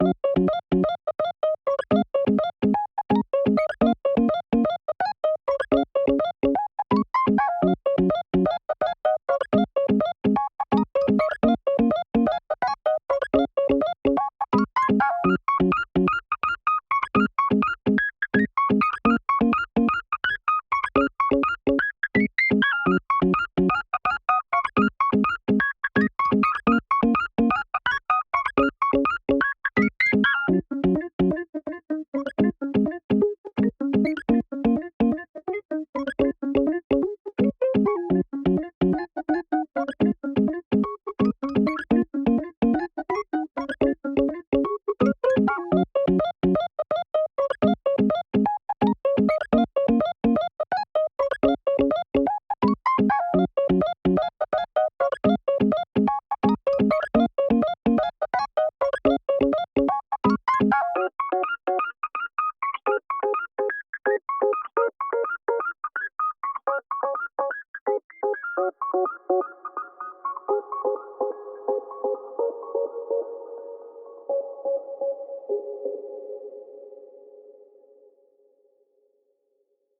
2. Hip Hop Instrumentals